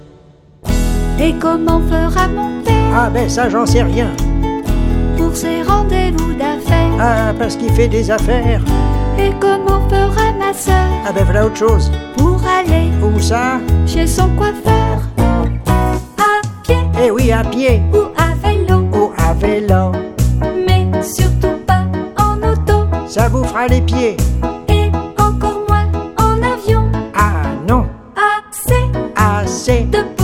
Chanson enfantine